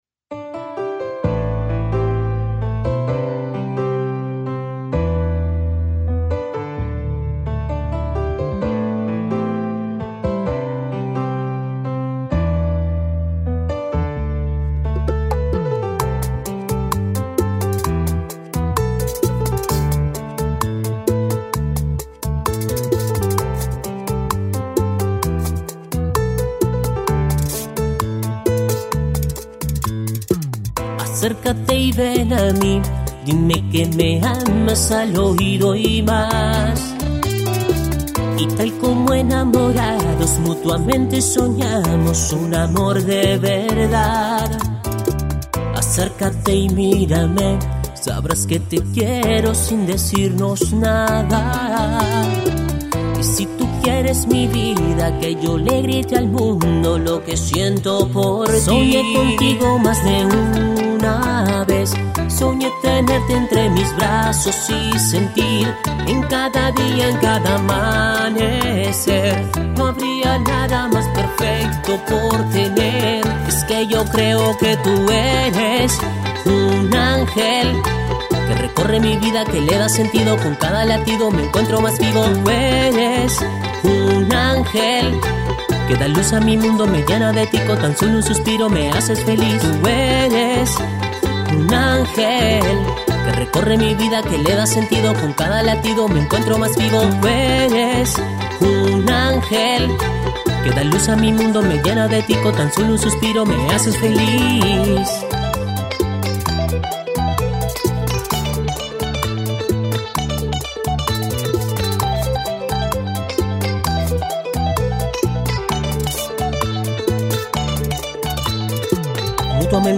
Categoría tropical